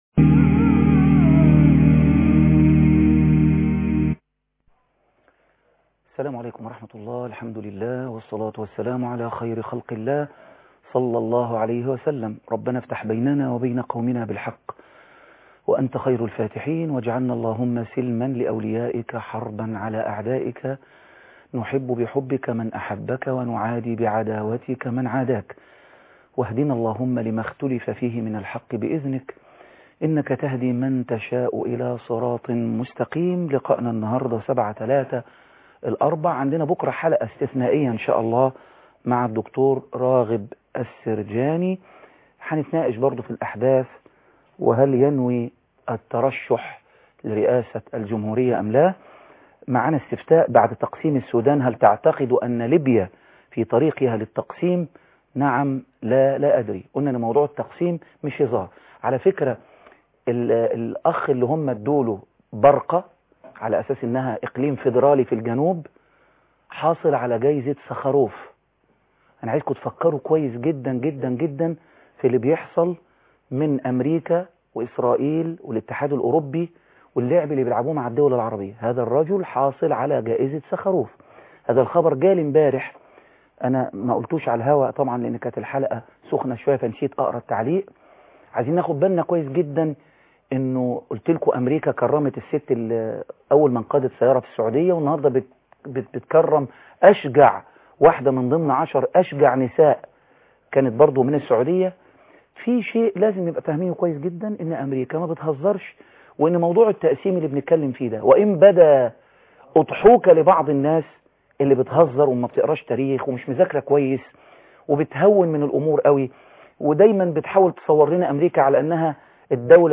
لقاء خاص مع خيري رمضان